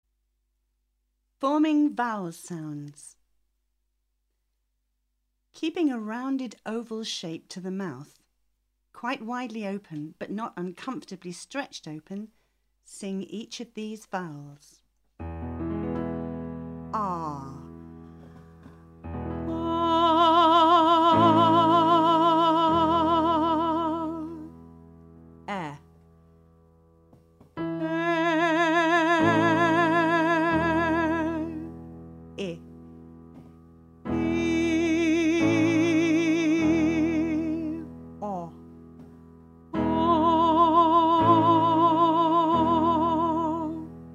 The 'Know Your Voice' CD is a clear and easy to follow explanation of singing technique with demonstrations and exercises for each aspect of producing the voice.